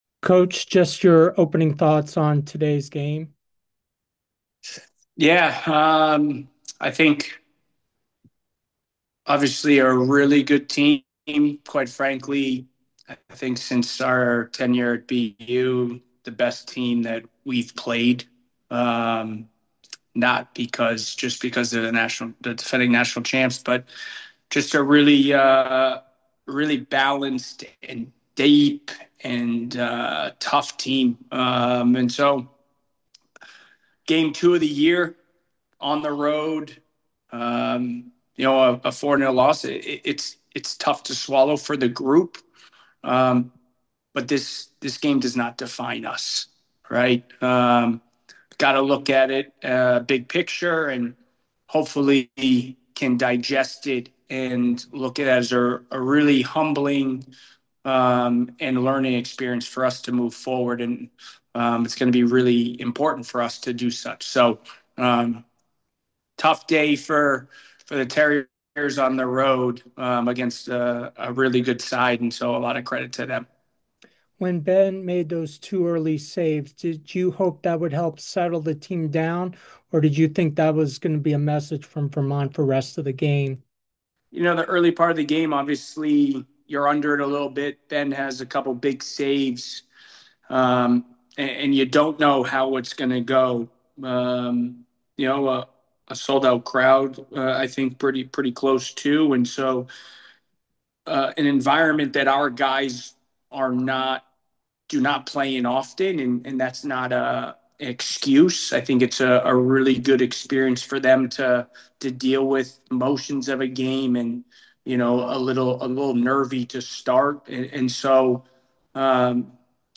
Vermont Postgame Interview